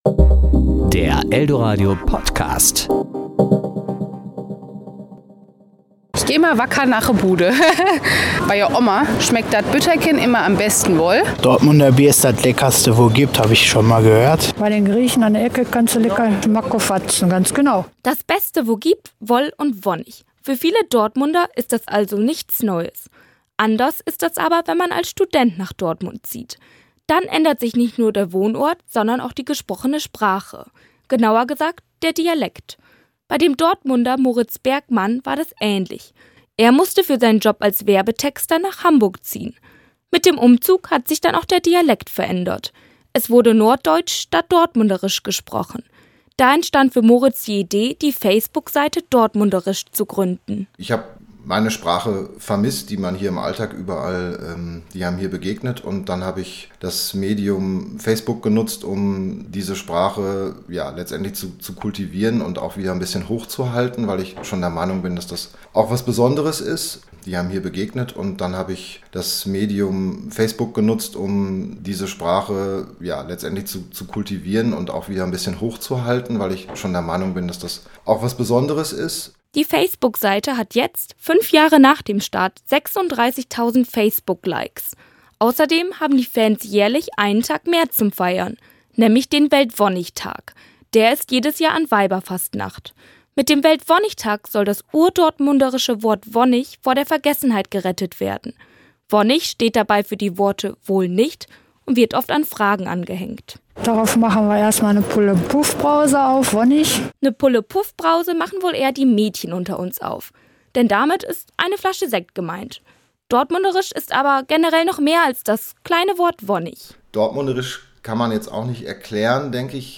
"Das Beste wo gibt, woll und wonnich" - Dortmund hat einen eigenen Dialekt: Dortmunderisch. Wie der genau geht?